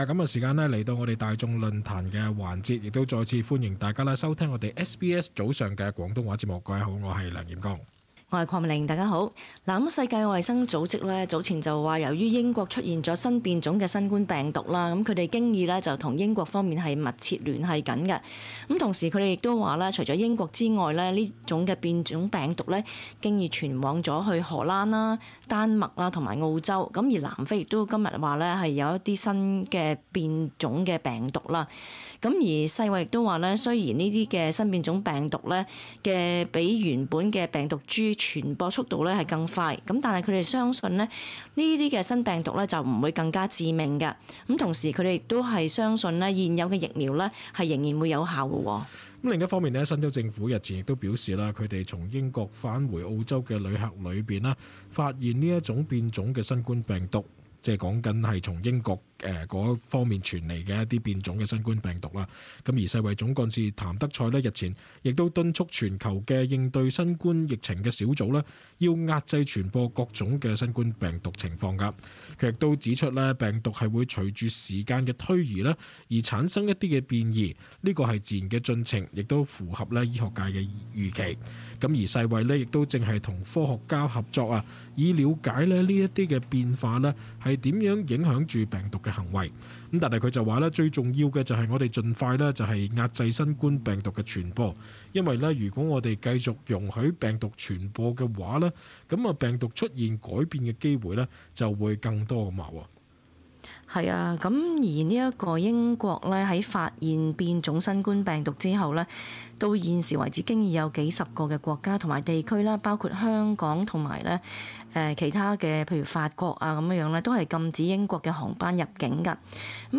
talkback_-_should_australia_close_border_with_uk_-_upload_0.mp3